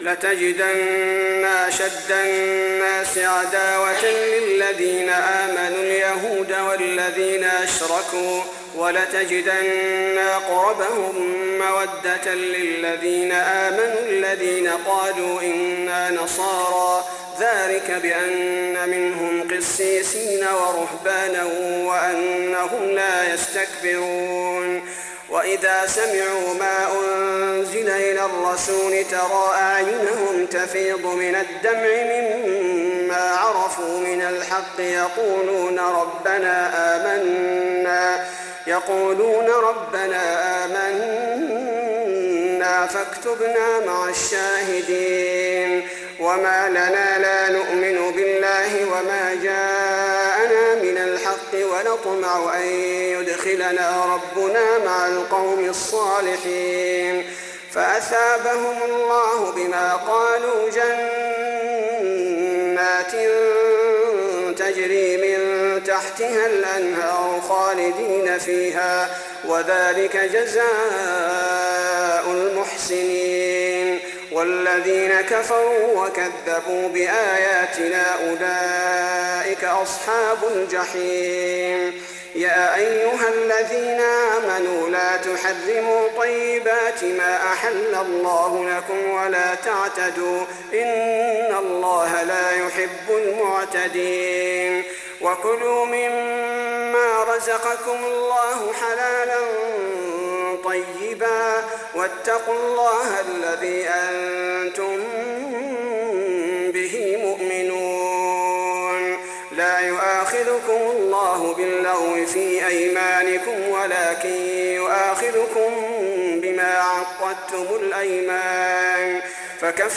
تهجد 1423